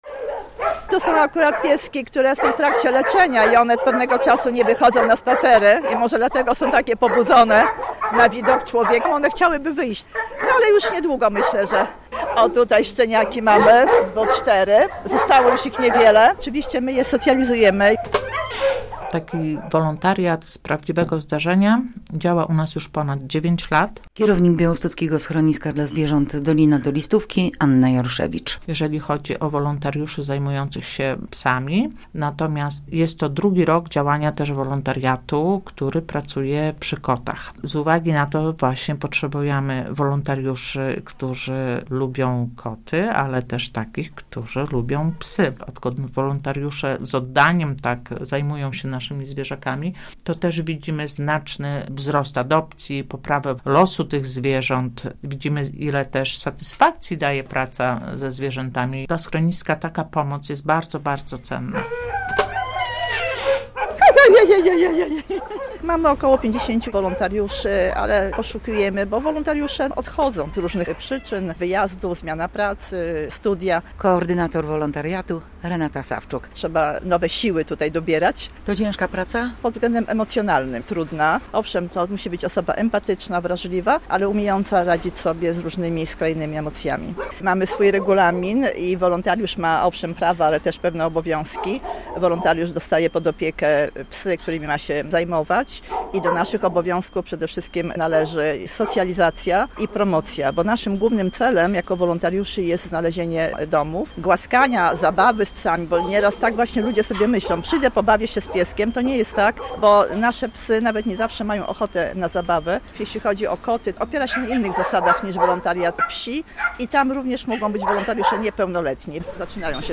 relacja
Naszym głównym celem jako wolontariuszy jest znalezienie zwierzakom domu - mówi jedna z wolontariuszek.